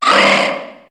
Cri de Skelénox dans Pokémon HOME.